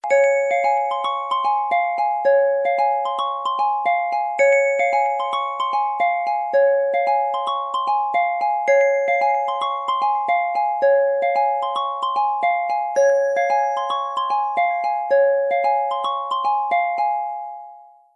blackberry-bells_24841.mp3